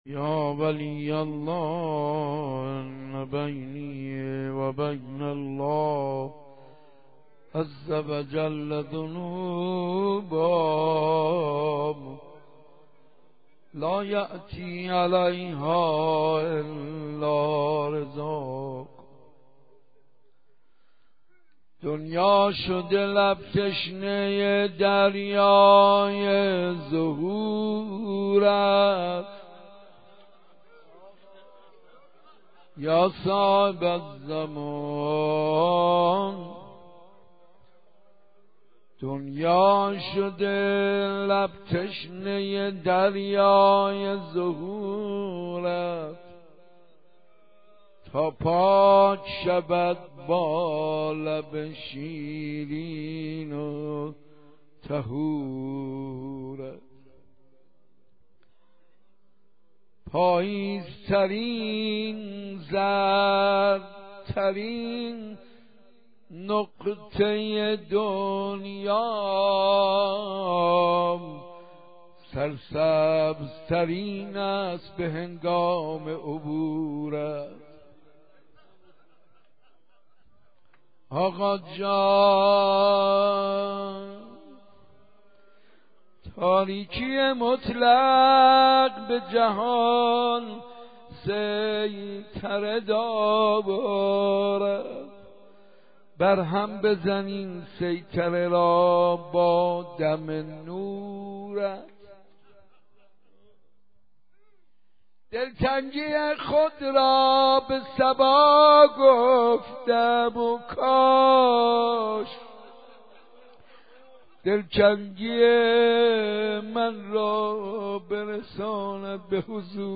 مناجات با امام زمان - عج